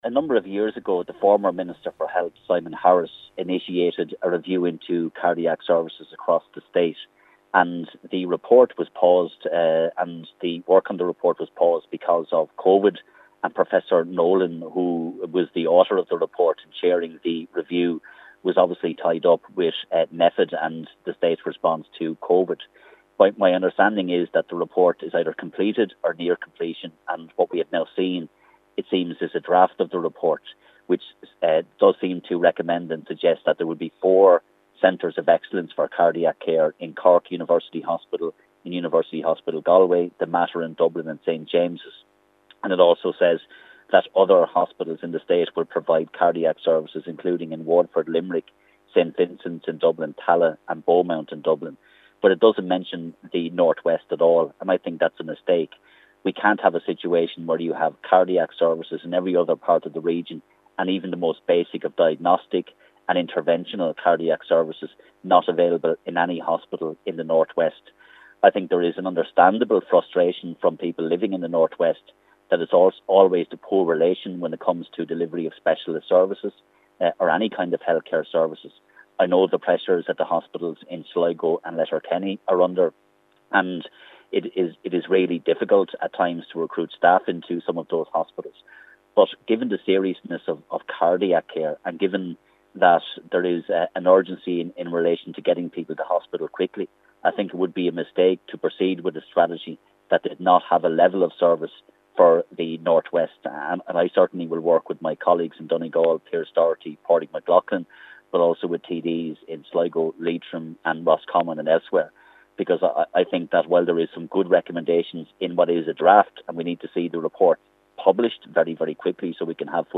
Sinn Fein’s Health Spokesperson, David Cullinane is calling for the urgent publication of the Nolan report.